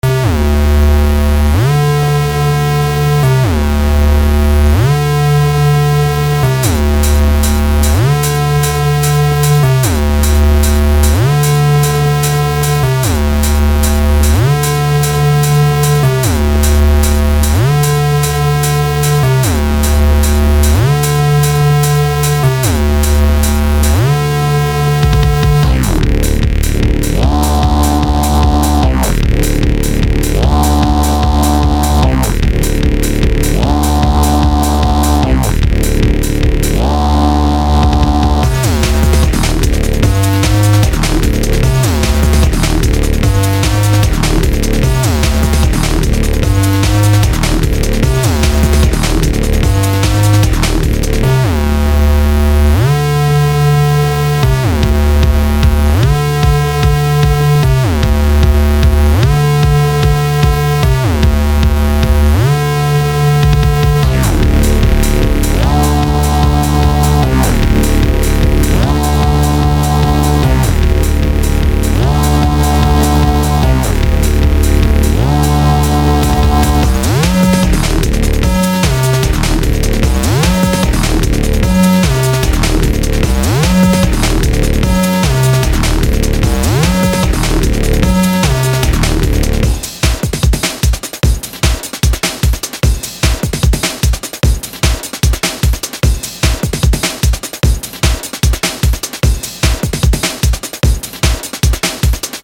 New Short Intro Track